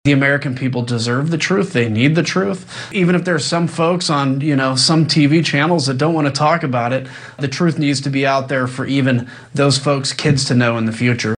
Speaking on ABC’s This Week, Kinzinger suggested that any politician who’s against the truth coming out may have been involved in the events.